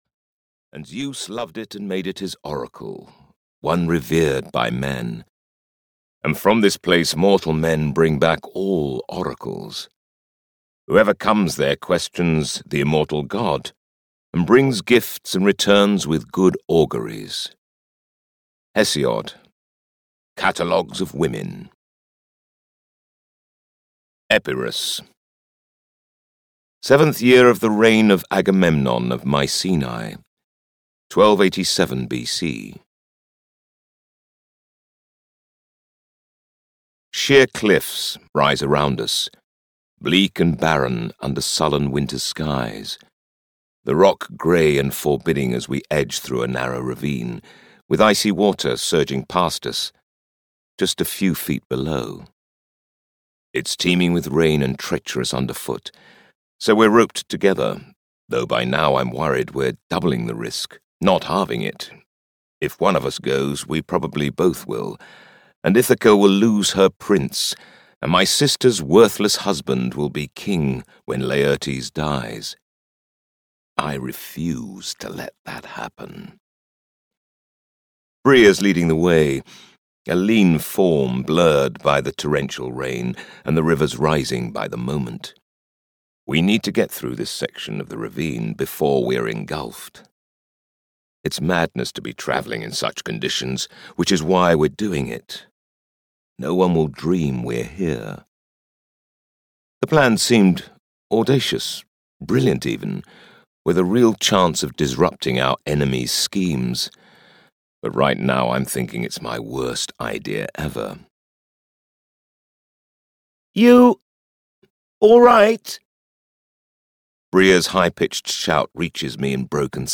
Sacred Bride (EN) audiokniha
Ukázka z knihy